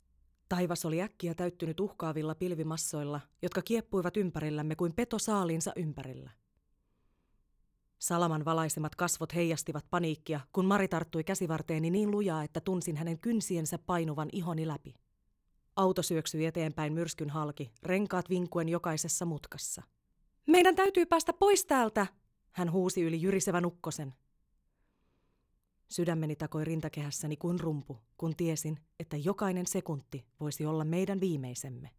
Äänikirjat
Jannitys-aanikirjanayte.mp3